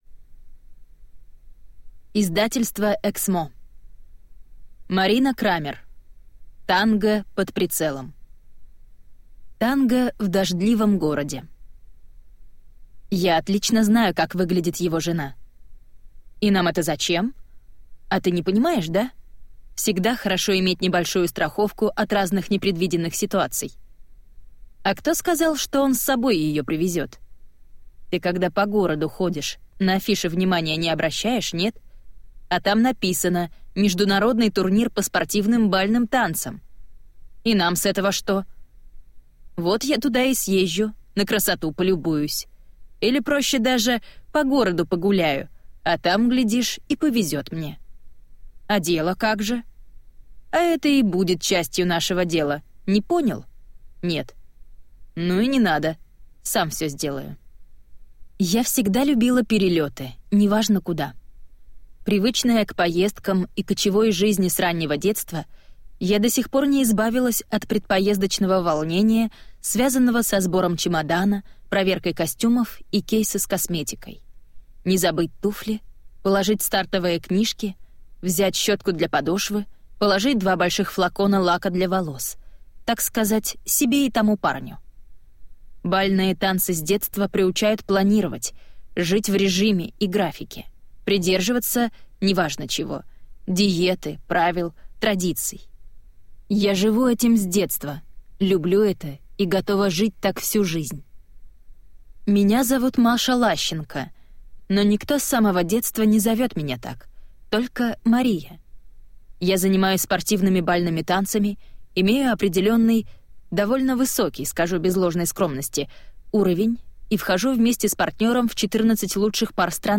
Аудиокнига Танго под прицелом | Библиотека аудиокниг